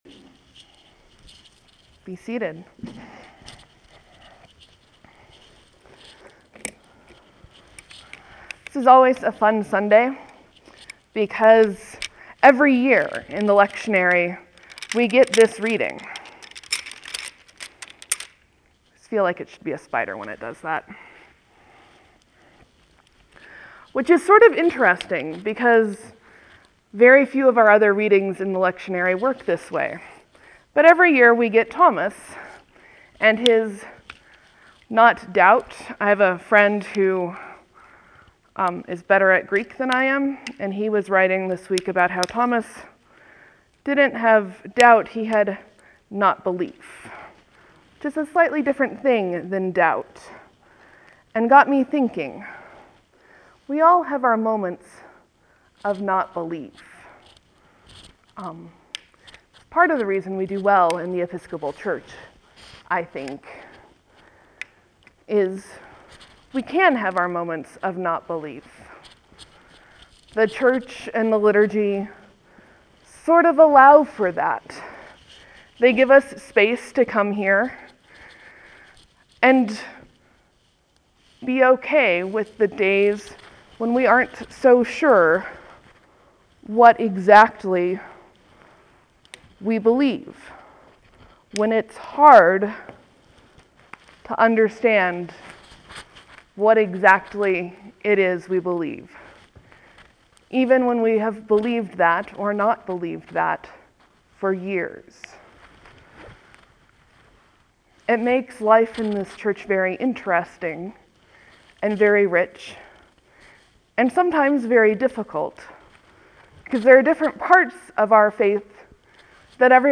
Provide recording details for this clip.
This is a fairly interactive sermon. I tried to make sure the mic picked up the gist but there may be parts that are tough to understand.